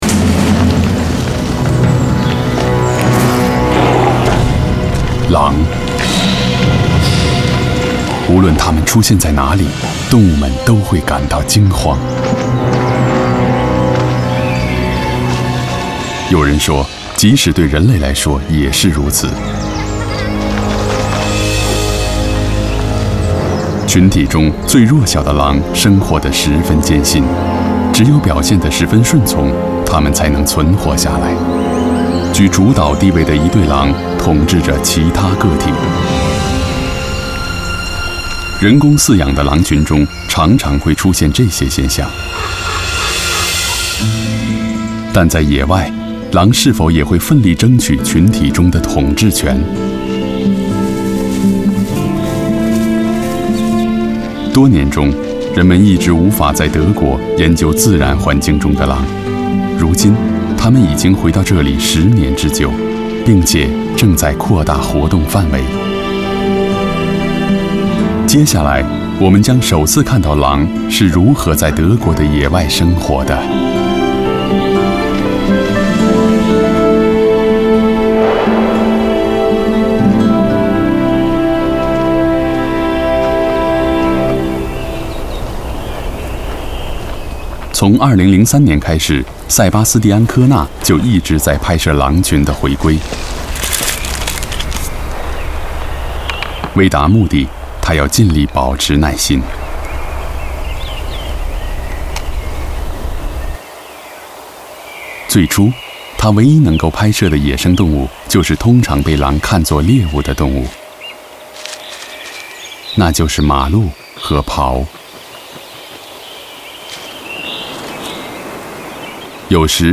• 男11 国语 男声 纪录片 野生狼群 动物类 大气浑厚磁性|沉稳|娓娓道来